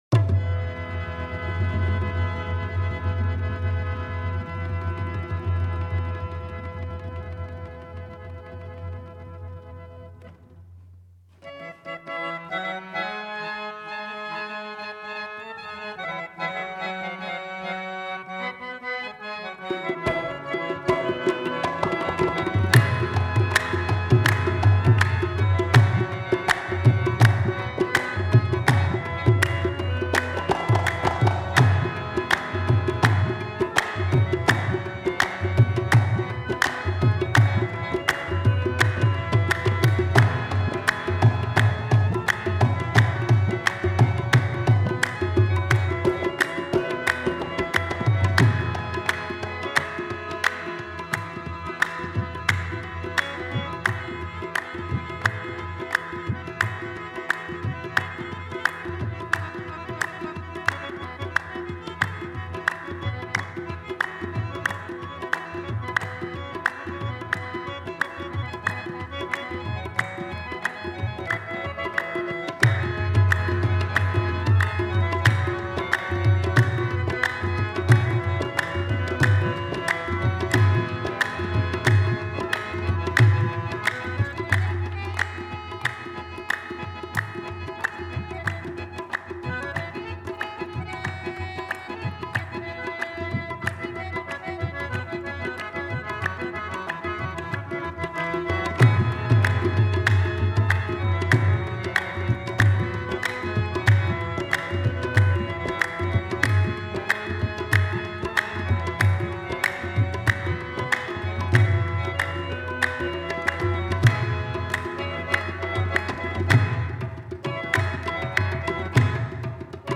Download MP3 Worlds Largest Collection of Qawwali
Farsi Kalam